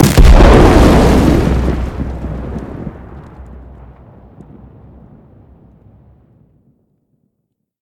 nuclear-explosion-2.ogg